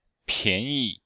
Cours-de-chinois-S2-L8-pian2yi5.mp3